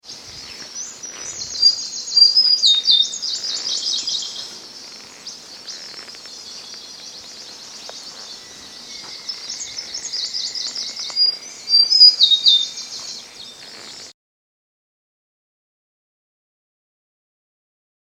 Suddenly I heard a high-pitched song that I recognised from Xeno-Canto Asia.
It was a Large-billed Leaf Warbler…
Large-billed Leaf Warbler
large-billed-leaf-warbler.mp3